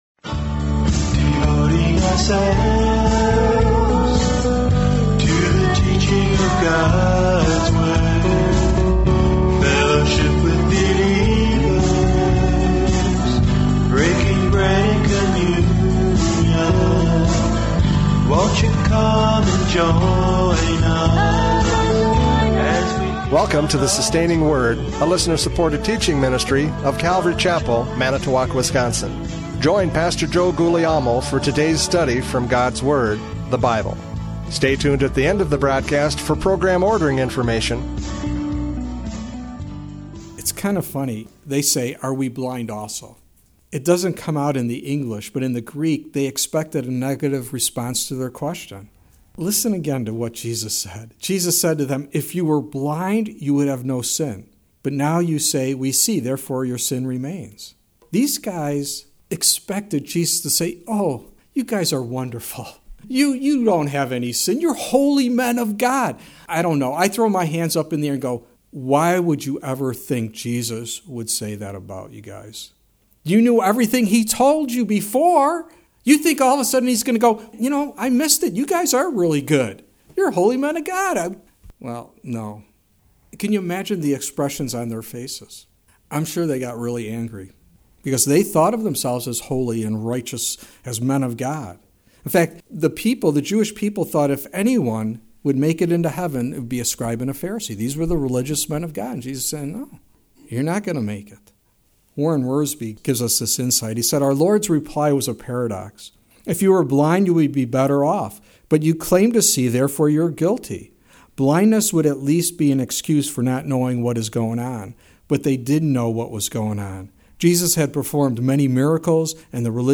John 9:35-41 Service Type: Radio Programs « John 9:35-41 Spiritual Blindness!